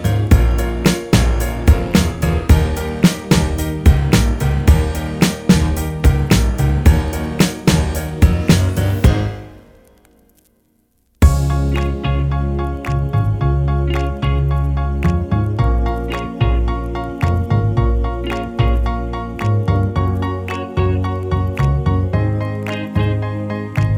Down 3 Semitones With No Backing Vocals Duets 3:14 Buy £1.50